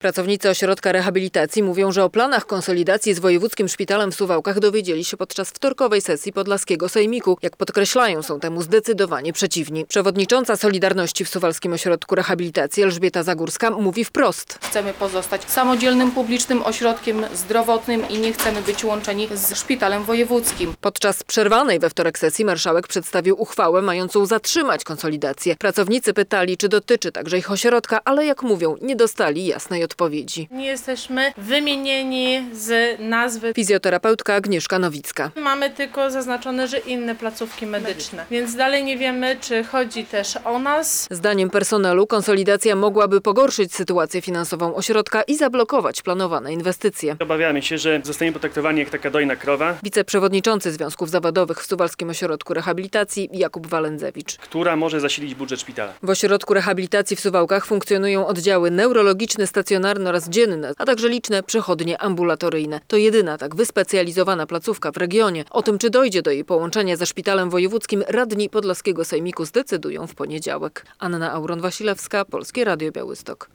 W Suwałkach w środę (26.11) pojawił się kolejny głos sprzeciwu wobec planowanej konsolidacji placówek medycznych w województwie podlaskim. Podczas zorganizowanej konferencji prasowej pracownicy Ośrodka Rehabilitacji wyrazili obawy związane z możliwym połączeniem ich placówki ze Szpitalem Wojewódzkim w Suwałkach.